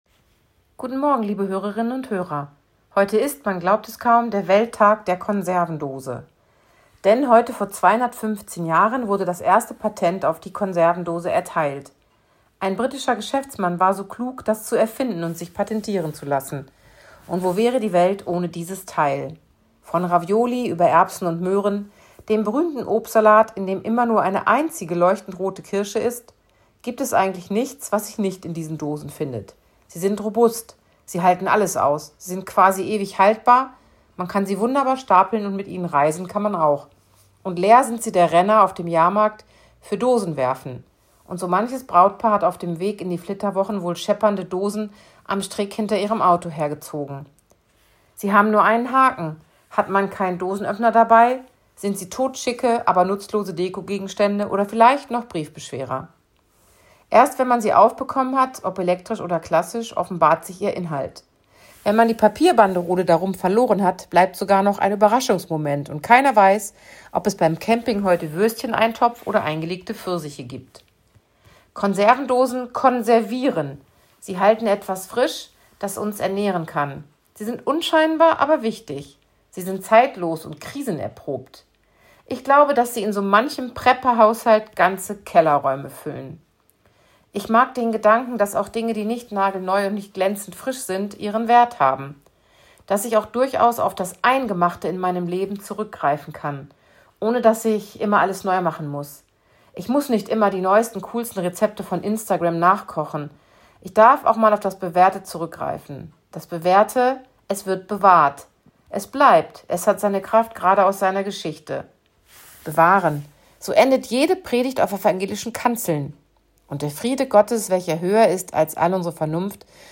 Radioandacht vom 25. August